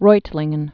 (roitlĭng-ən)